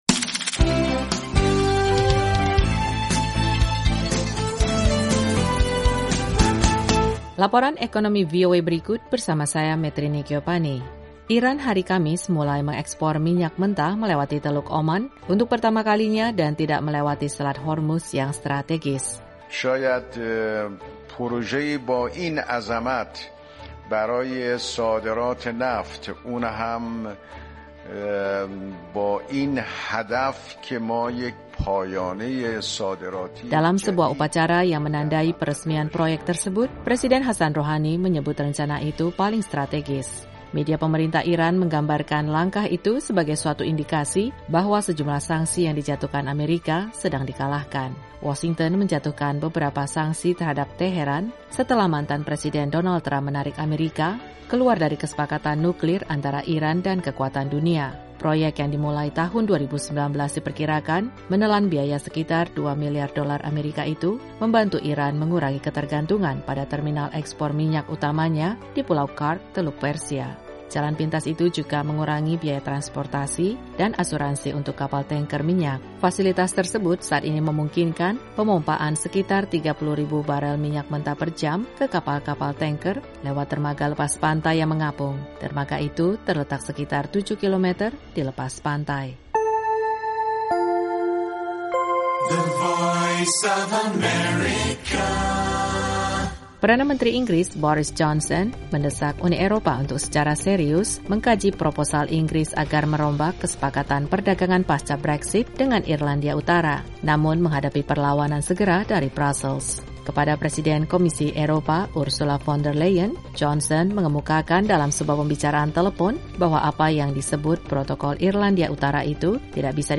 Laporan Ekonomi VOA hari ini mengenai Iran melakukan ekspor minyak mentah melewati Selat Hormuz. Simak juga informasi terkait Perdana Menteri Inggris yang mendesak Uni Eropa agar 'serius' mengkaji proposal atas Irlandia Utara.